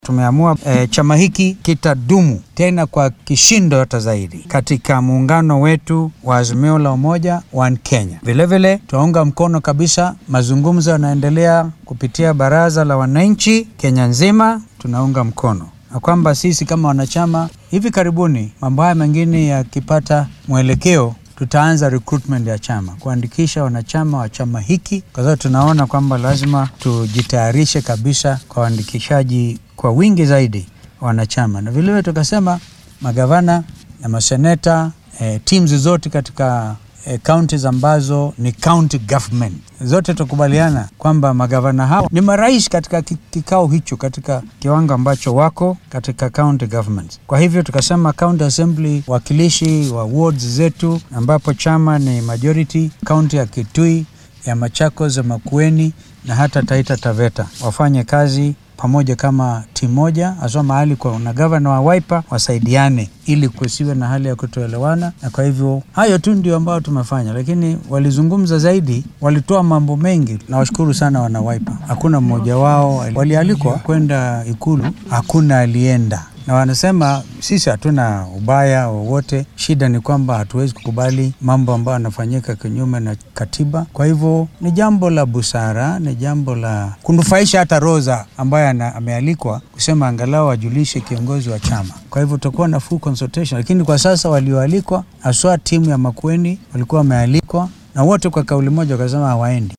Madaxa xisbigan Kalonzo Musyoka oo shir jaraaid qabtay ayaa sheegay in Wiper ay ka sii mid ahaan doonto garabka mucaaradka.